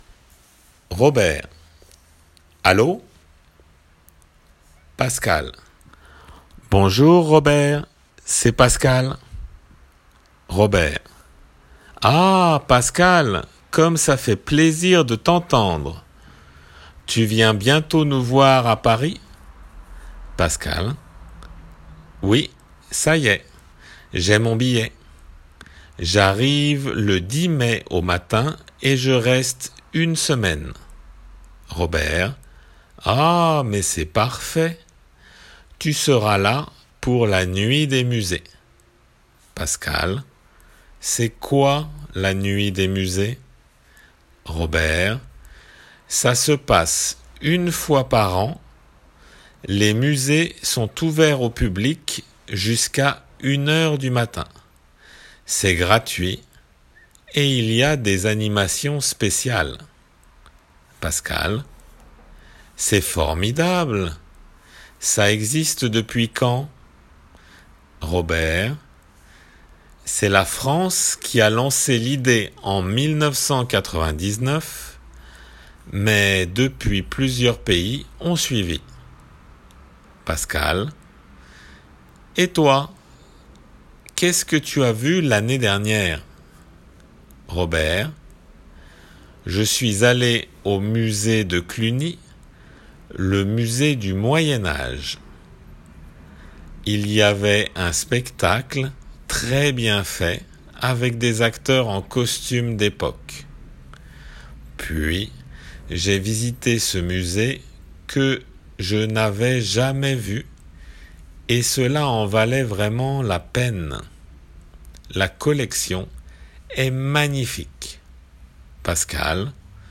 聞き取りの練習です。